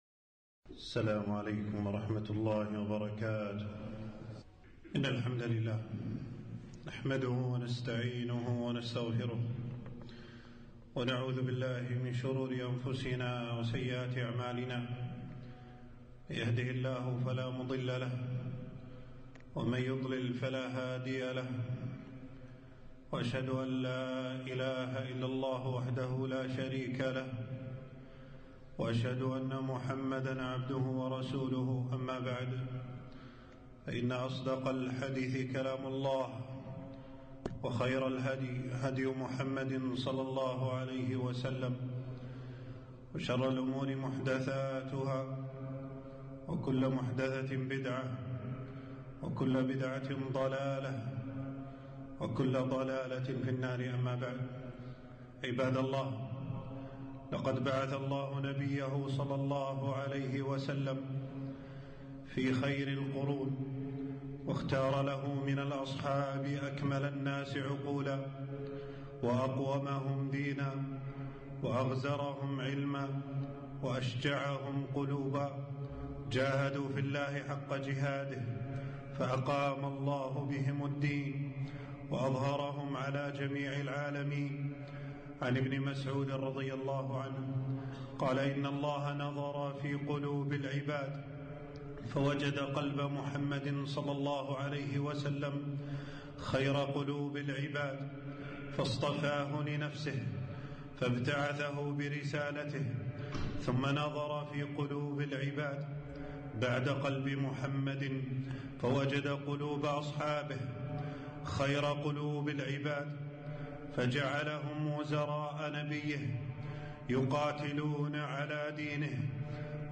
خطبة - فضائل العشرة المبشرين بالجنة